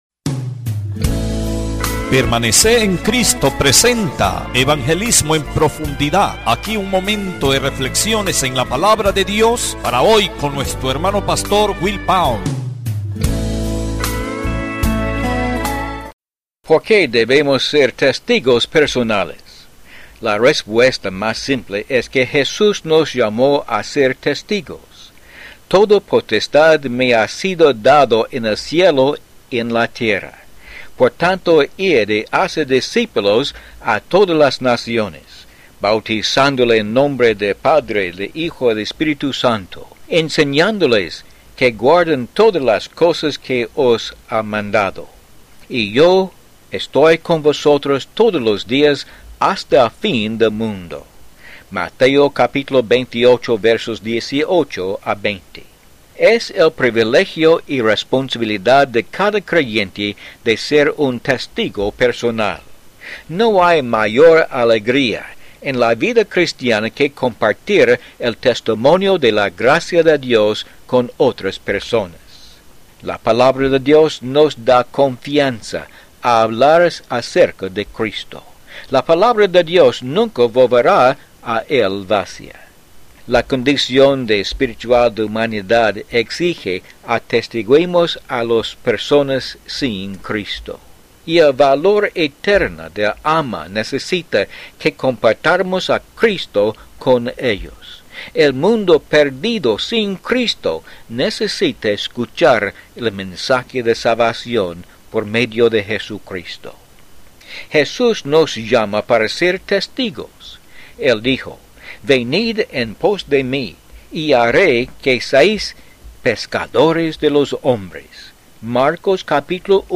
Disfrute estos programas de cinco minutos Cristianos de radio.